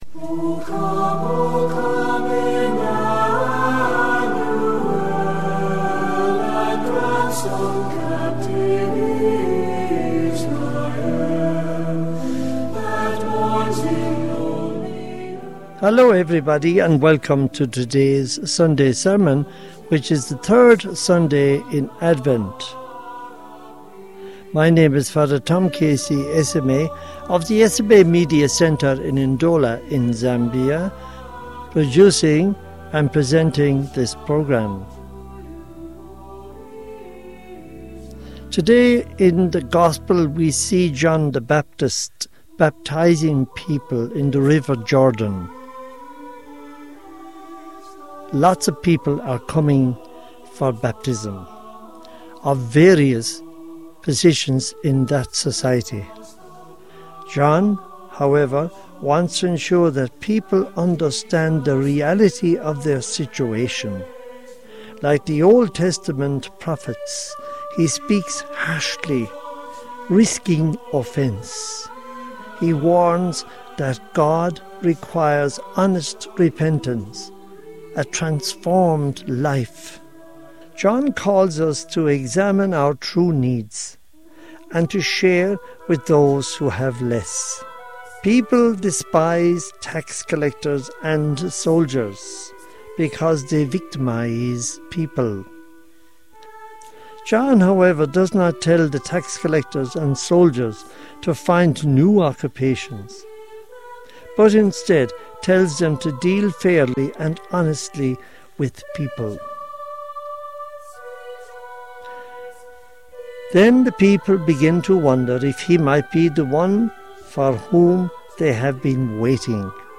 Homily for the 3rd Sunday of Advent Year C, 2025